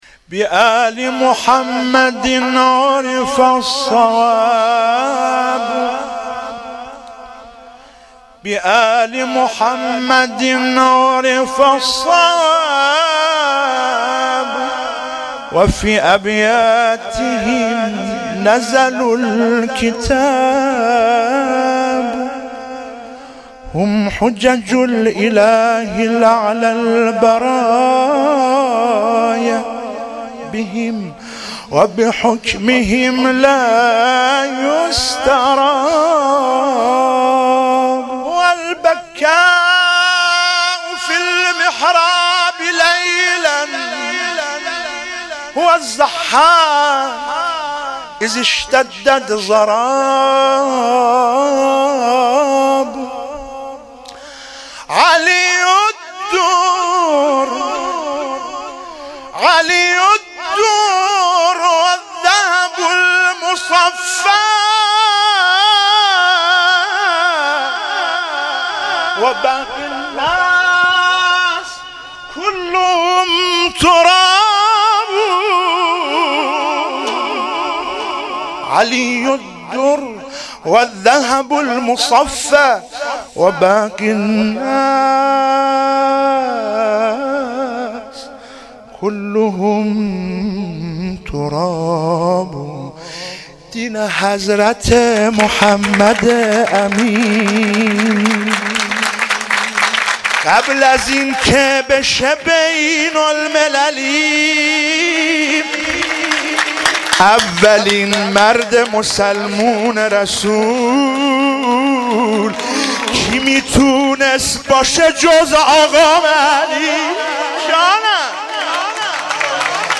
مبعث 97 - سرودعربی فارسی - به آل محمد عرف